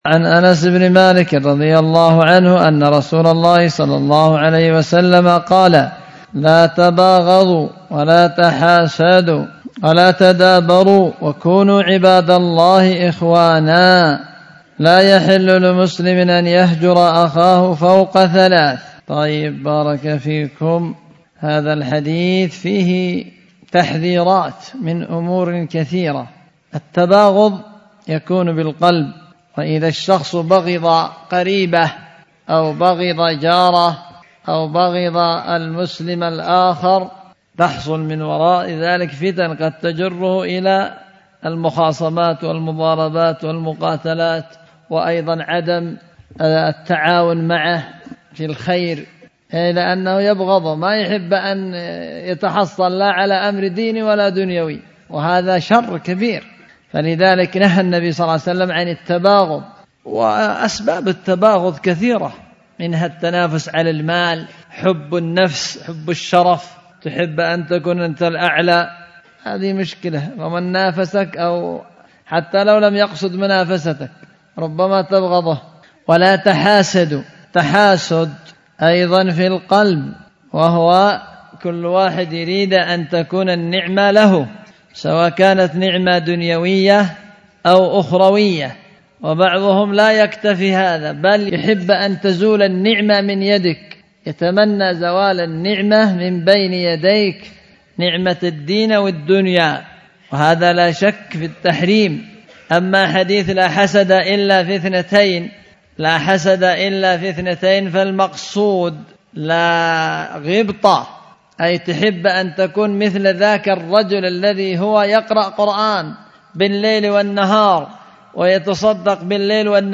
ألقيت في دار الحديث بمسجد السلف الصالح بذمار